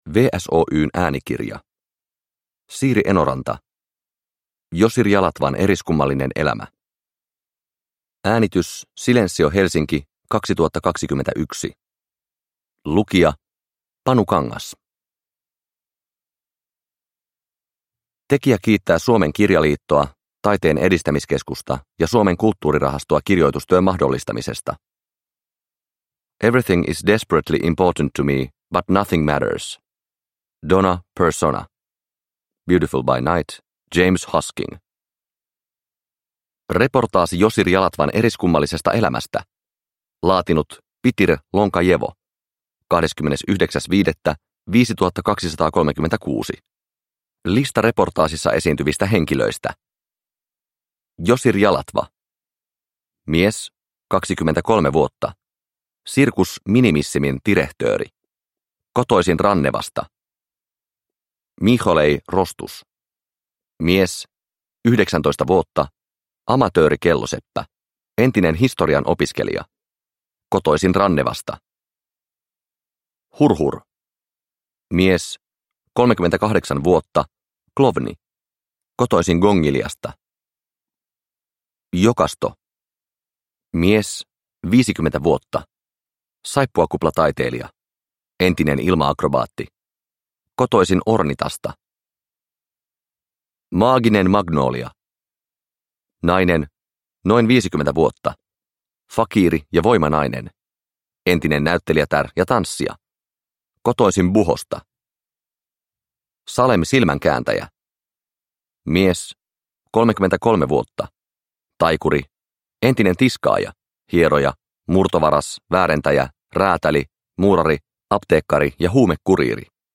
Josir Jalatvan eriskummallinen elämä – Ljudbok – Laddas ner